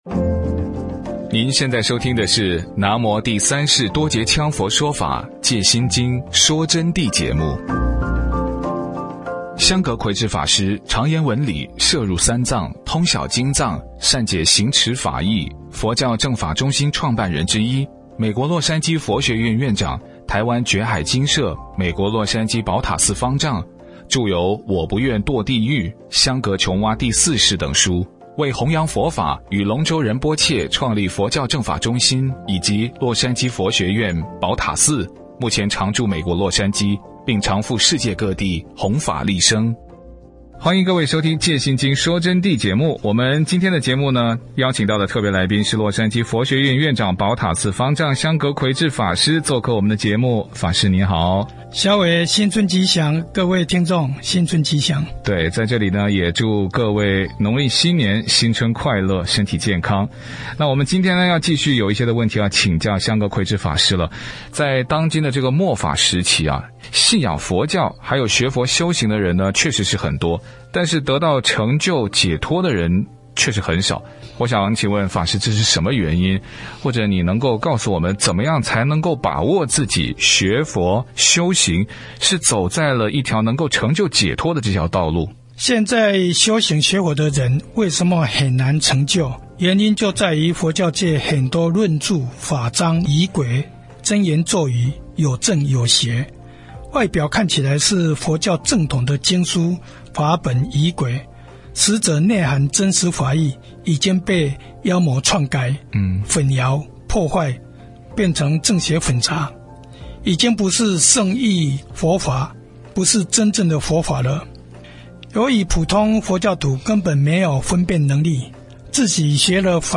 佛弟子访谈（二十八）为什么学佛修行的人很多而得到成就解脱的人少？